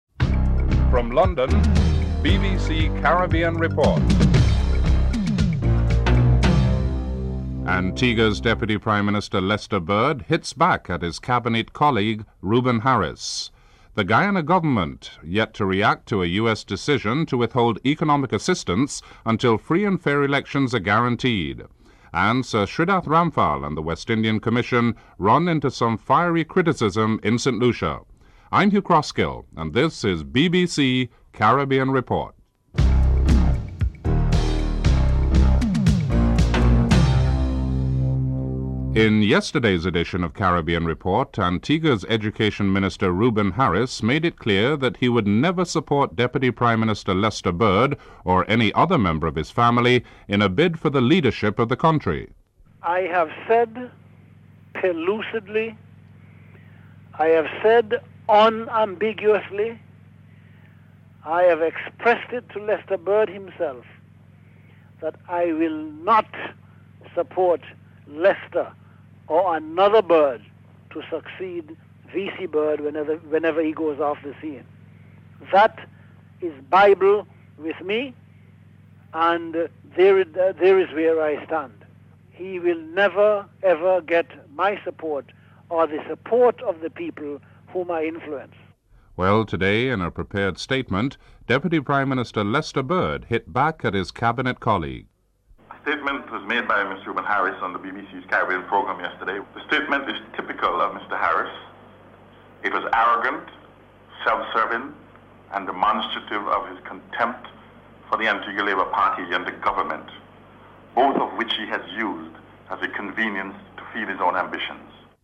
The British Broadcasting Corporation
1. Headlines (00:00-00:37)